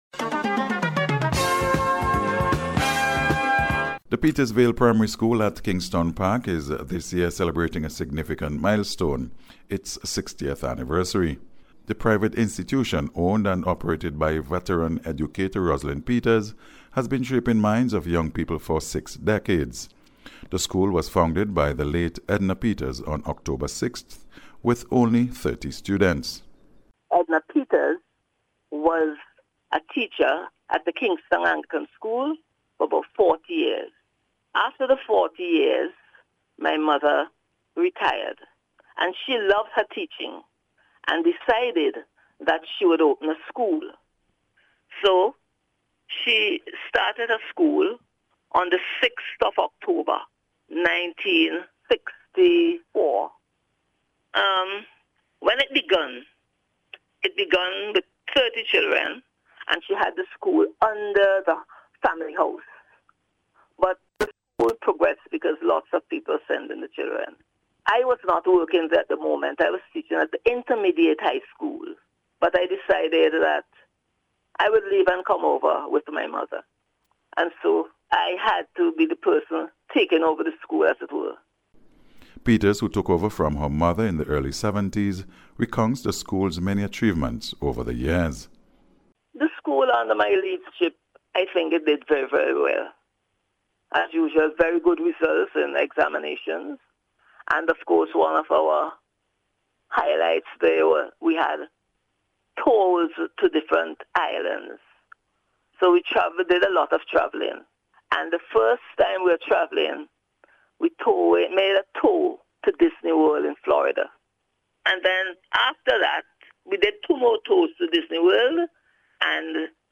NBC’s Special Report- Wednesday 13th August,2025
PETERSVILLE-ANNIVERSARY-REPORT.mp3